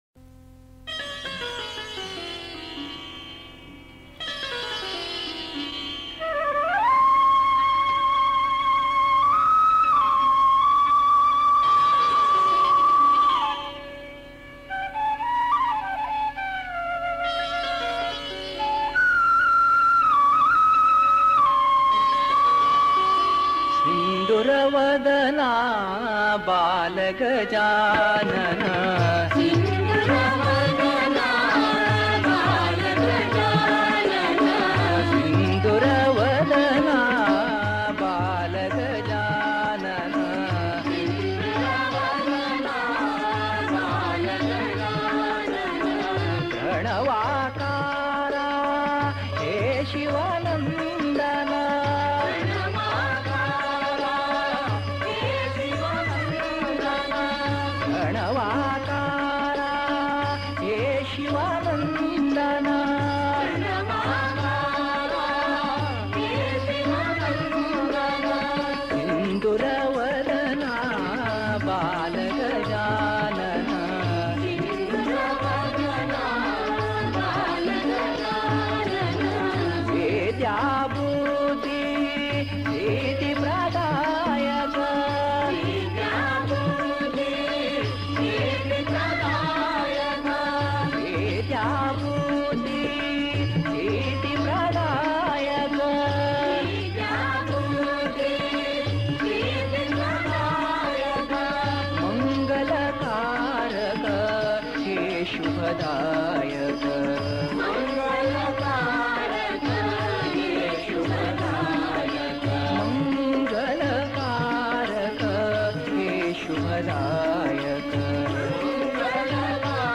Author adminPosted on Categories Ganesh Bhajans